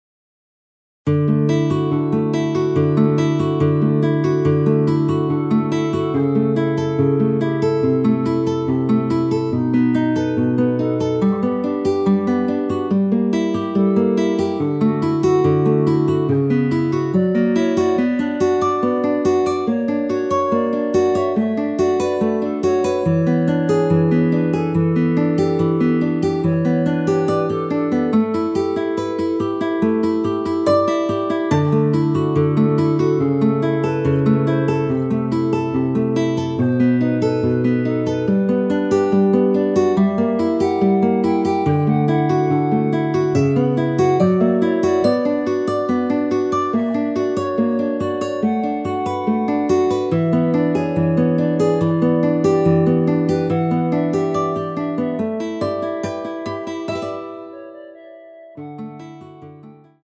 원키에서(+5)올린 멜로디 포함된 MR입니다.
앞부분30초, 뒷부분30초씩 편집해서 올려 드리고 있습니다.
중간에 음이 끈어지고 다시 나오는 이유는